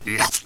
spearman_attack3.wav